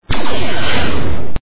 The sound bytes heard on this page have quirks and are low quality.
S.T.N.G. - PHOTON TORPEDO # 2 1.28